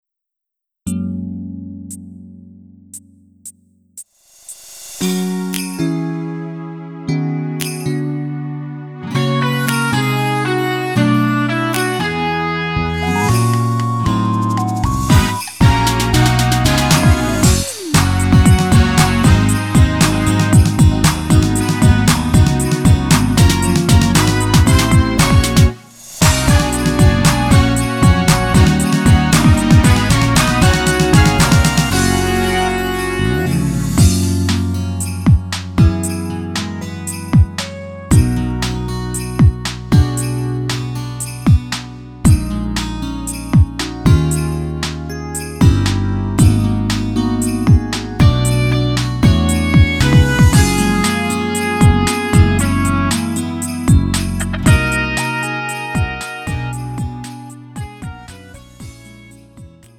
음정 -1키 3:13
장르 구분 Lite MR